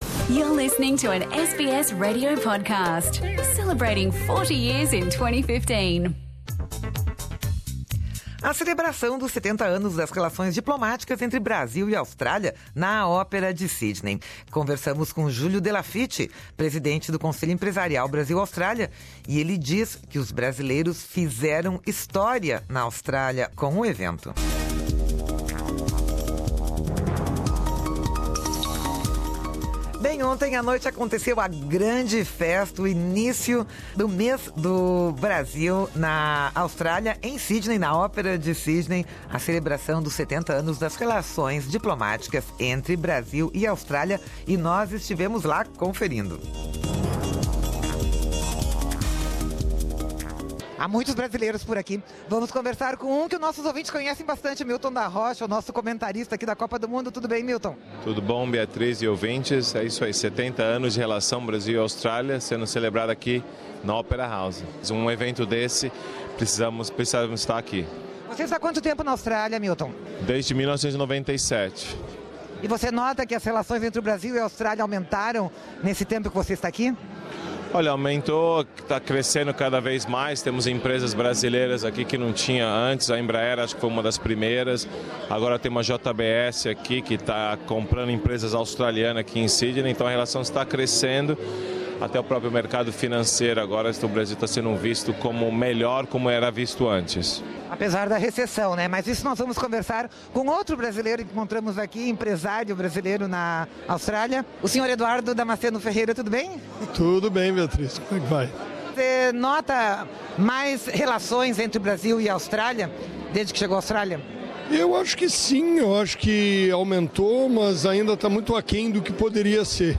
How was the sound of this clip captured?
A semana foi agitada para os brasileiros em Sydney, celebrando o Dia Nacional e também os 70 anos das relações diplomáticas entre Brasil e Austrália. Na festa da Ópera, promoção do Consulado Geral do Brasil em Sydney e do AUBRBC, entrevistamos também os empresários